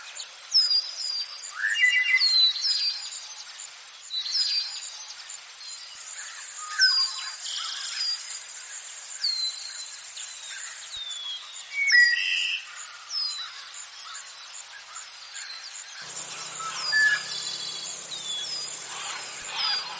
Cicadas Summer
Cicadas Summer is a free nature sound effect available for download in MP3 format.
# cicadas # summer # insects # ambient About this sound Cicadas Summer is a free nature sound effect available for download in MP3 format.
469_cicadas_summer.mp3